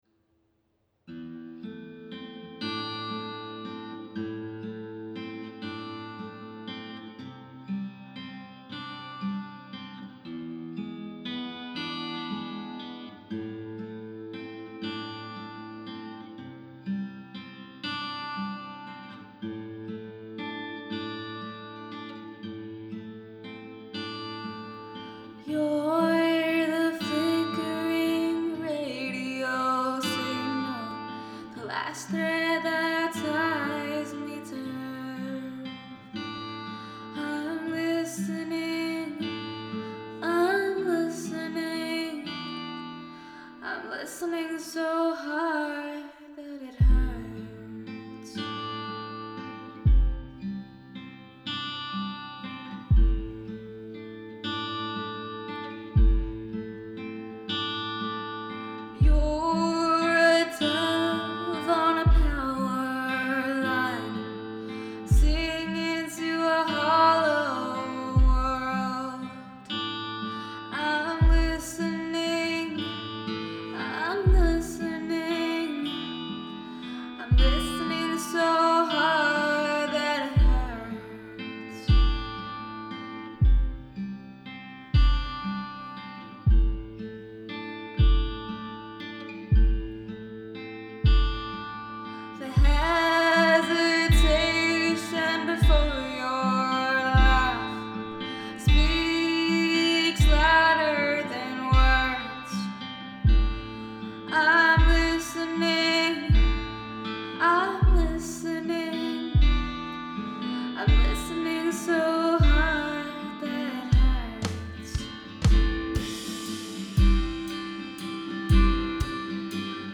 Hi guys, here's a pop/folk/rock (honestly I'm not sure what genre to call it) song that I wrote and produced called Listening Listening.